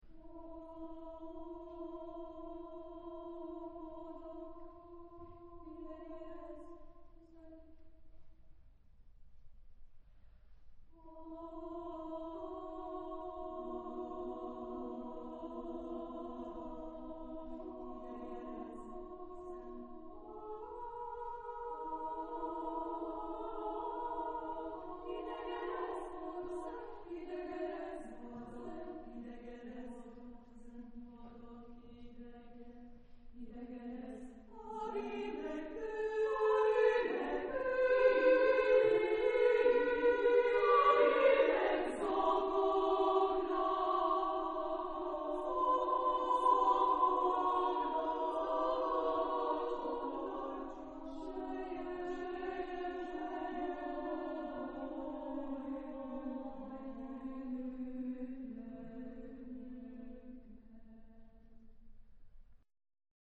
Genre-Style-Forme : Pièce vocale ; Profane
Type de choeur : SSSAAA  (6 voix égales de femmes )